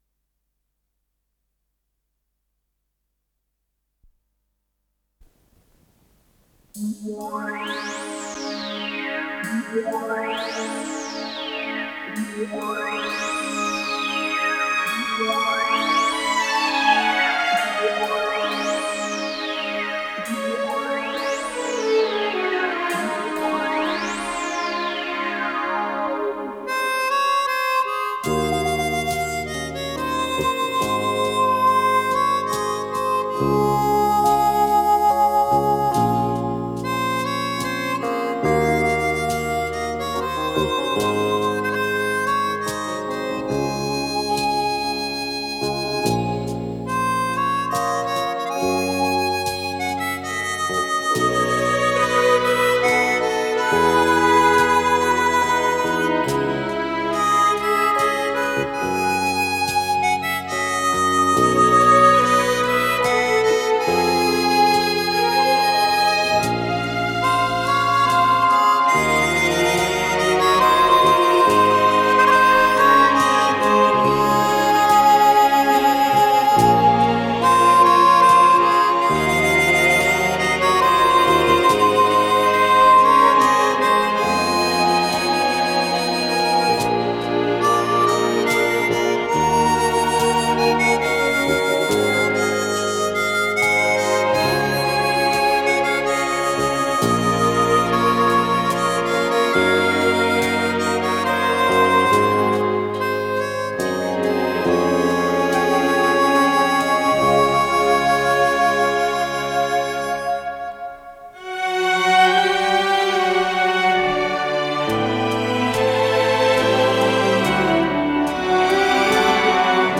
ПодзаголовокПьеса
Соло на губной гармошке
ВариантДубль моно